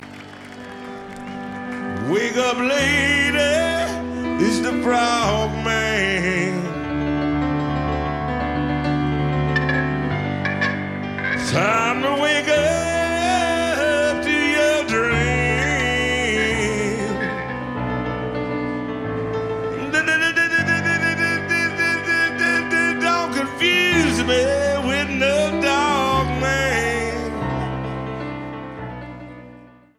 Blues
Jamband
Rock